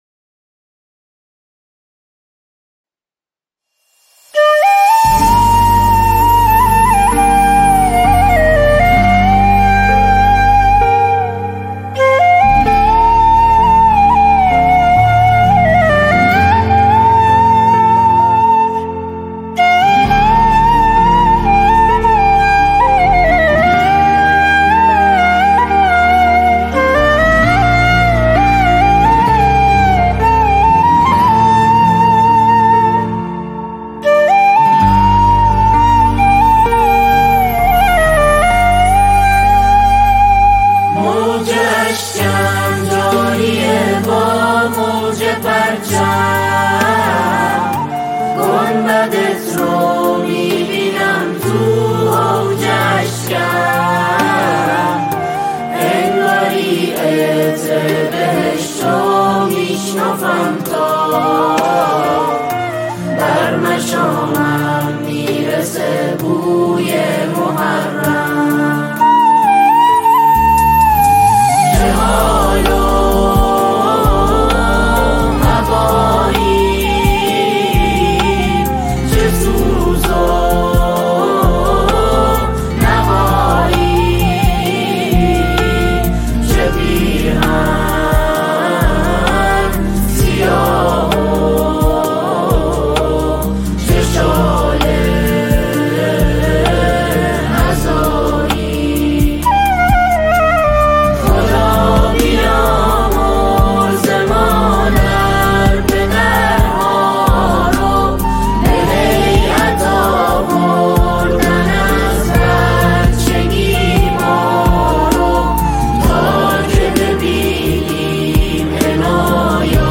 تکخوان
جمع‌ خوانان